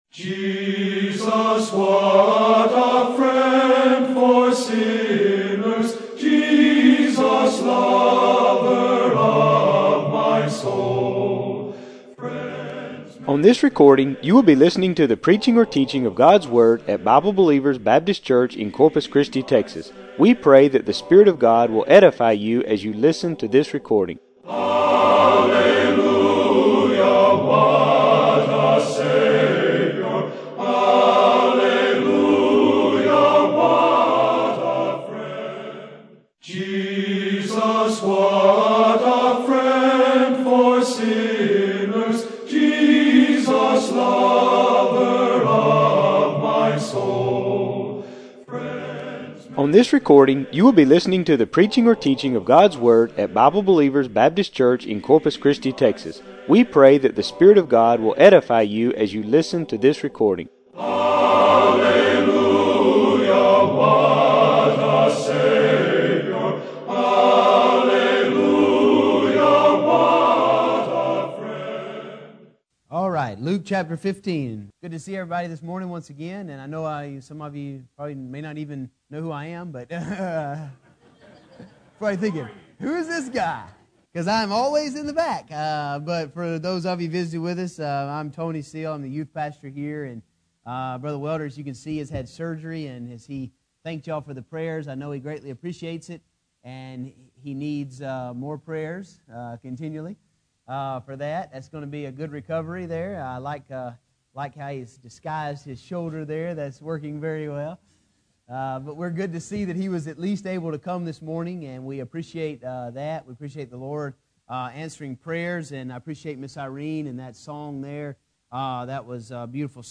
This sermon from the Prodigal Son encourages teens to stay put and quit thinking in terms of leaving home the moment they turn 18. You will grow spiritually much stronger if you remain at home rather than traveling away to college.